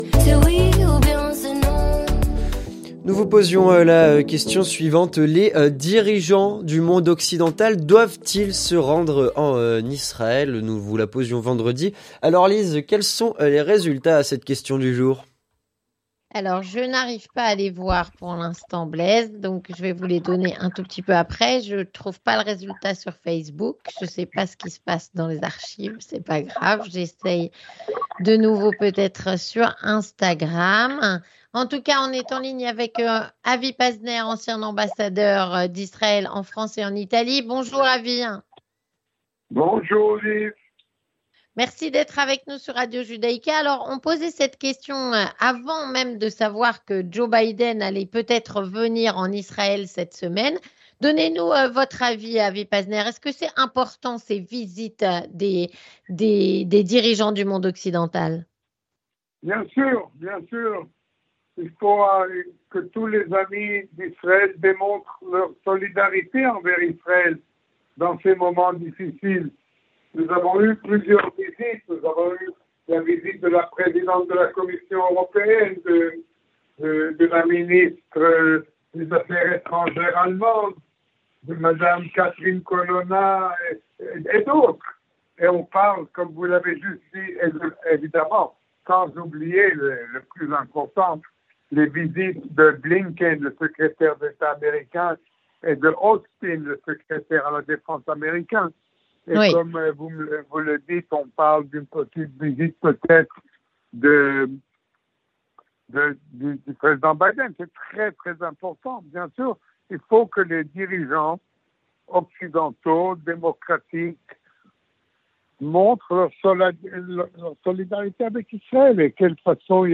Avi Pazner, ancien Ambassadeur d'Israël en France et en Italie, répond à "La Question Du Jour".